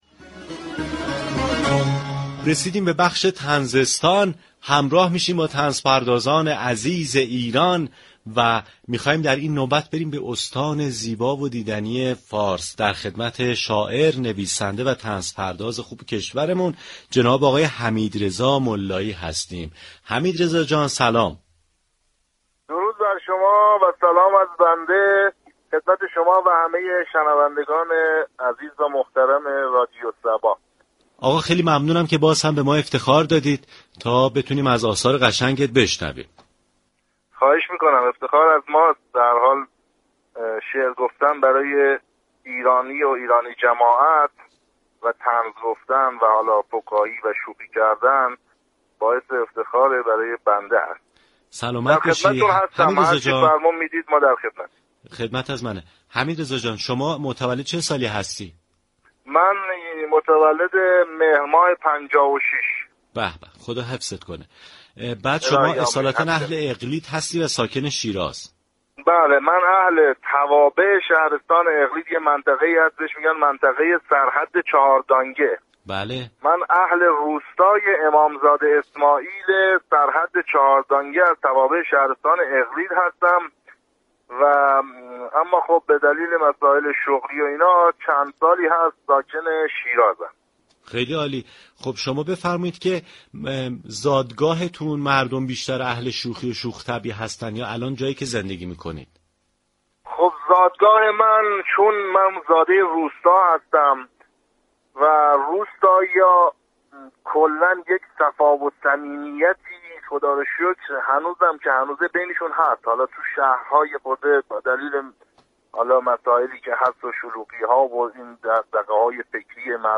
شنونده گفتگوی برنامه لیموترش